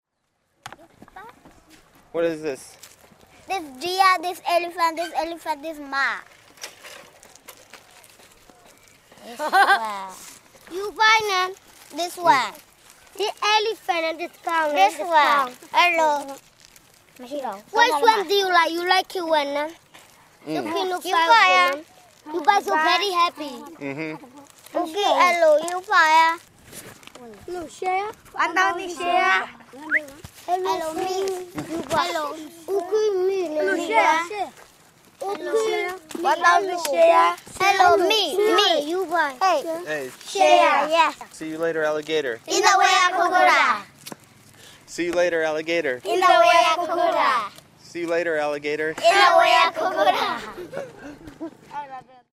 Children playing, Old Bagan
Children playing in Old Bagan, Myanmar.